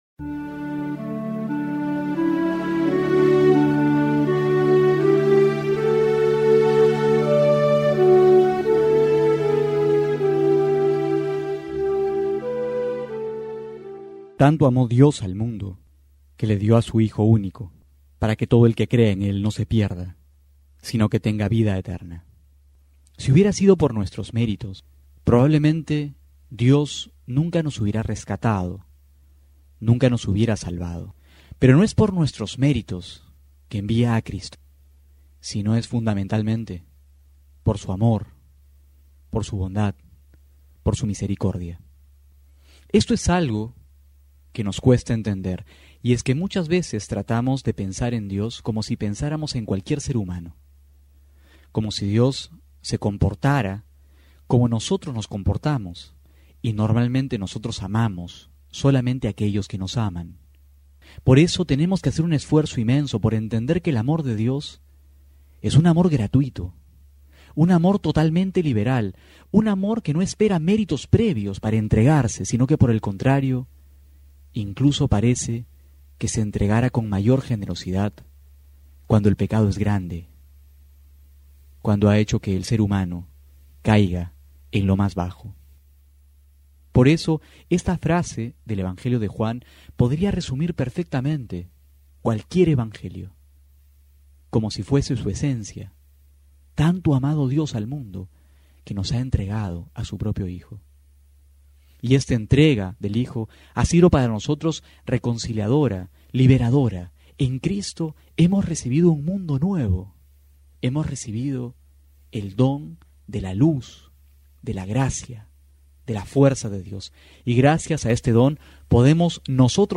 abril18-12homilia.mp3